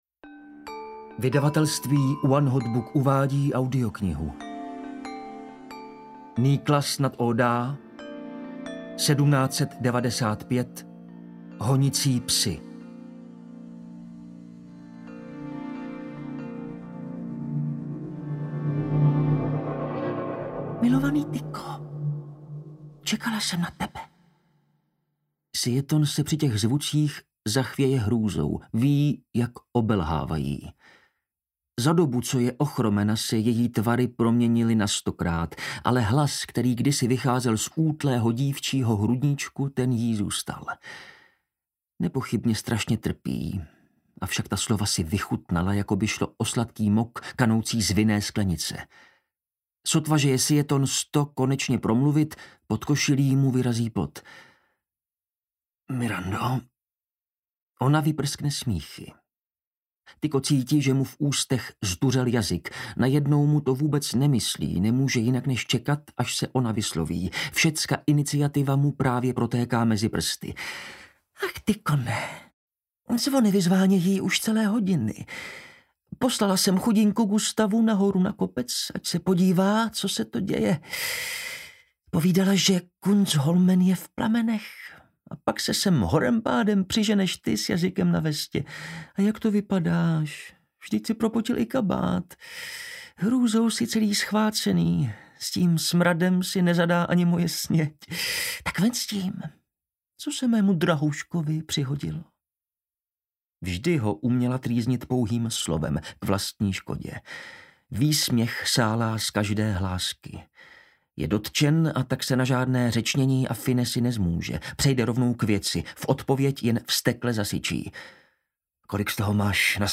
1795 – Honicí psi audiokniha
Ukázka z knihy
• InterpretDaniel Bambas